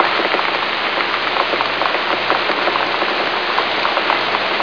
Silnejsi dazd - dokola.wav